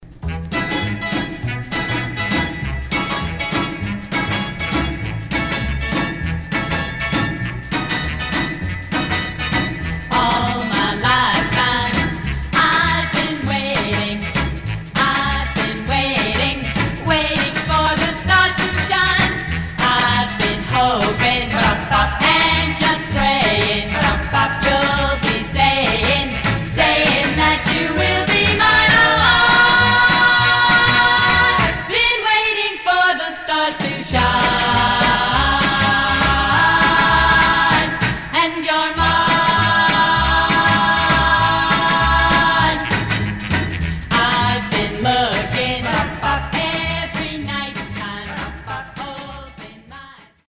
Surface noise/wear
Mono
White Teen Girl Groups